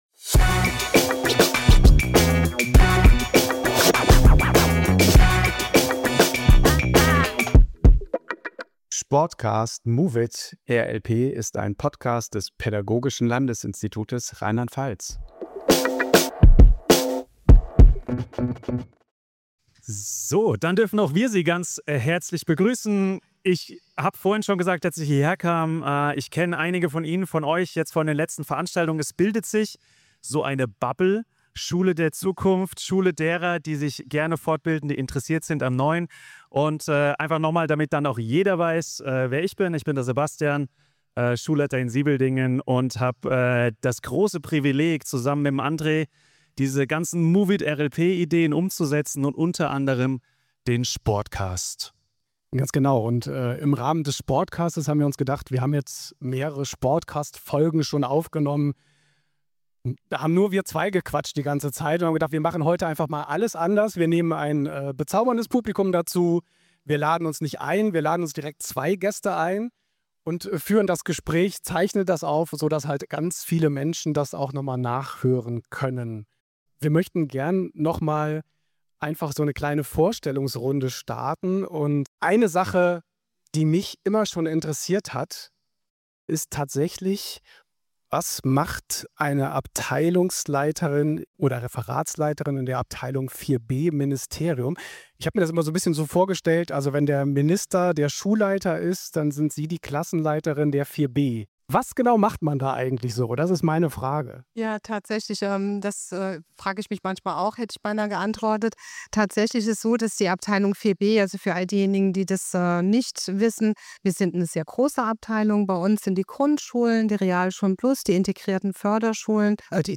Live aufgezeichnet im Historischen Museum Speyer 7.11.2025